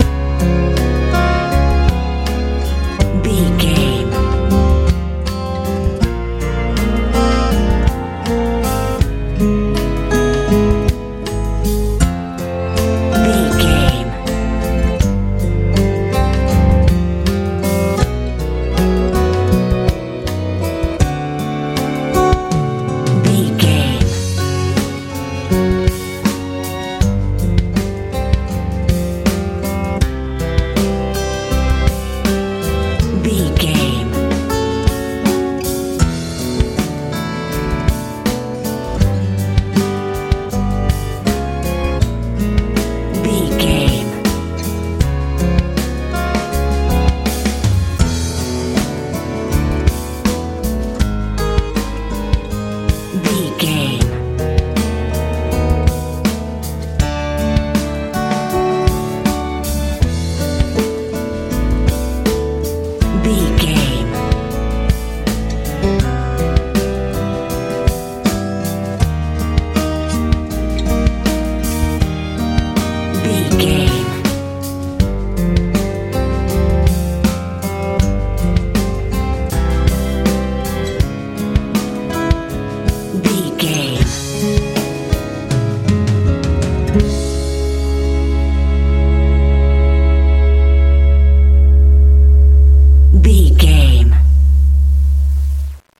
dramaic country feel
Ionian/Major
C♯
calm
energetic
acoustic guitar
strings
bass guitar
drums
mellow
smooth
soft